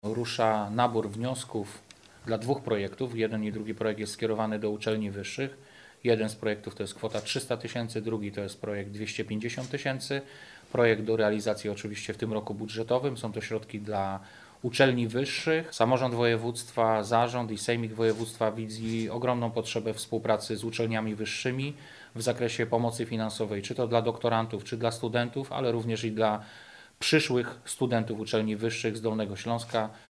Mówi Wojciech Bochnak, Wicemarszałek Województwa Dolnośląskiego.